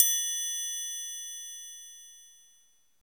Index of /90_sSampleCDs/Roland L-CD701/PRC_Latin 2/PRC_Triangles